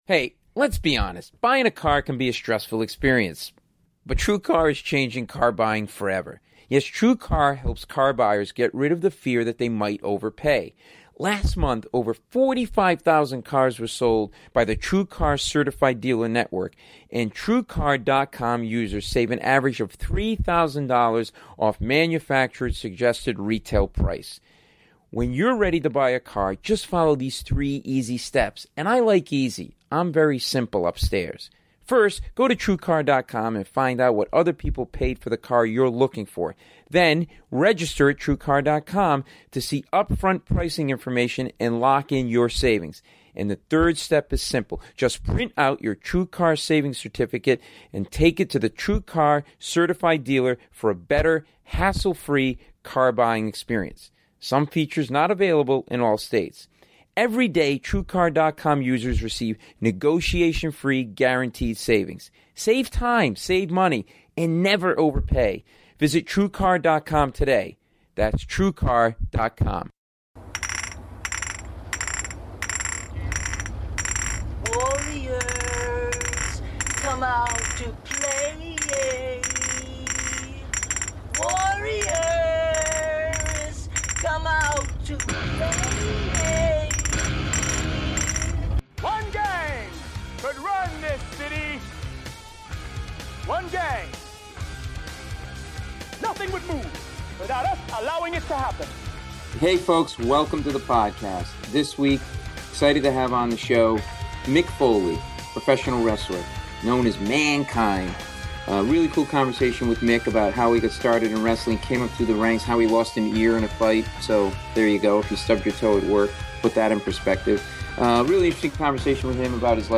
This is an awesome conversation with Mick about his days as a wrestler, how he got started, what is was like coming up through the ranks, how he developed his wrestling persona and what went through his mind as his ear was being ripped off during a match, I'm guessing, "where did I park my car?" Mick is a great interview and also talks about his current tour doing stand up/story telling, which everyone should see.